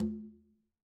Quinto-HitN_v2_rr2_Sum.wav